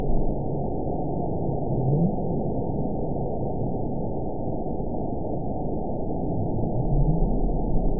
event 922124 date 12/26/24 time 22:49:24 GMT (5 months, 3 weeks ago) score 9.16 location TSS-AB04 detected by nrw target species NRW annotations +NRW Spectrogram: Frequency (kHz) vs. Time (s) audio not available .wav